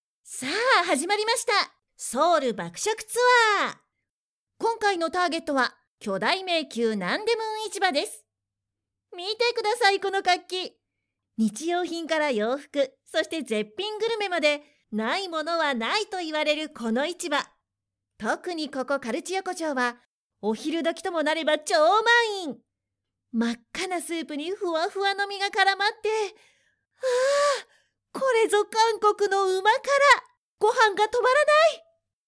クセのない素直な声質で、明るく元気なものから落ち着いたものまで対応可能です。
– ナレーション –
テンションの高い、勢いのある
female59_8.mp3